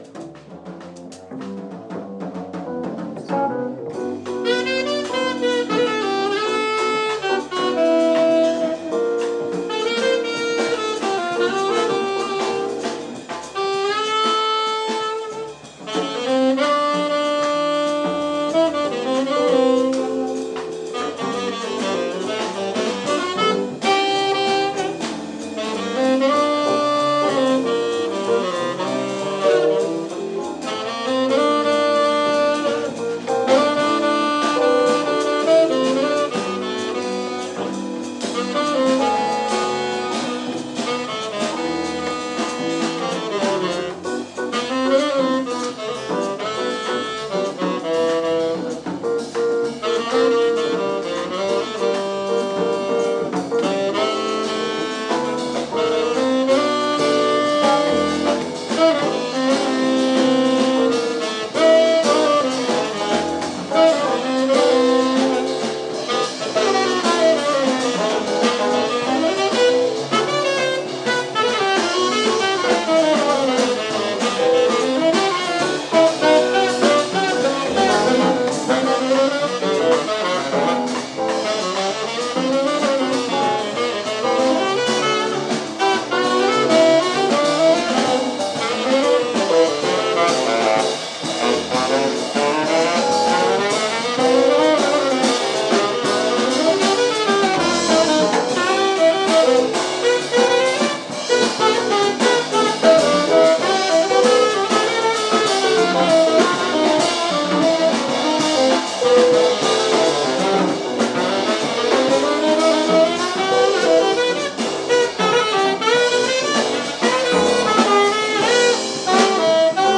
In onore alla favolosa esperienza dell’EnoJazz - ormai appuntamento fisso delle sere del Barga Jazz Festival – il BJC ripropone le fantastiche jam session sul proprio palco.